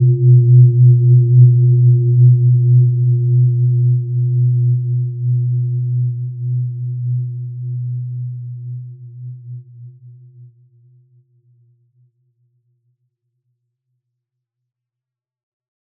Gentle-Metallic-3-B2-mf.wav